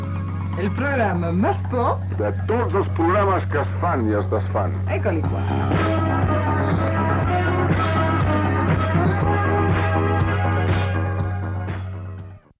Eslògan del programa
FM